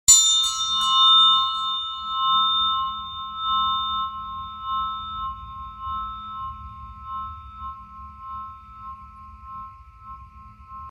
This sound 👁 963Hz sound effects free download